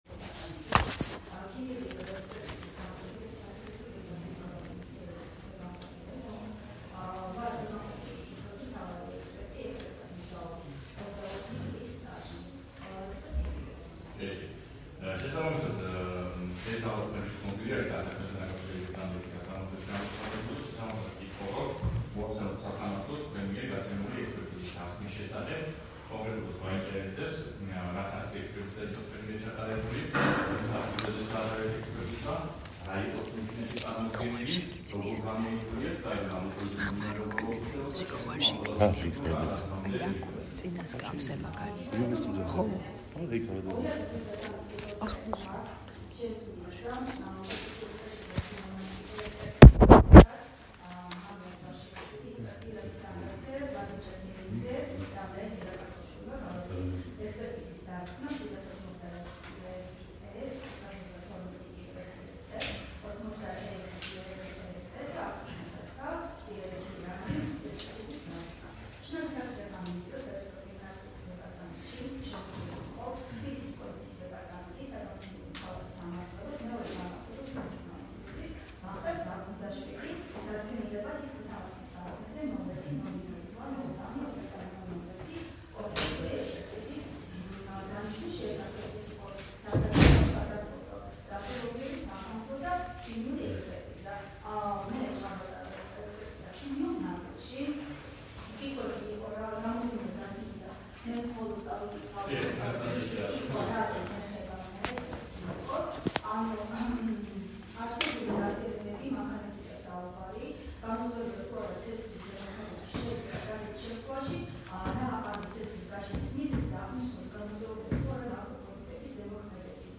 სასამართლო პროცესის აუდიო ჩანაწერი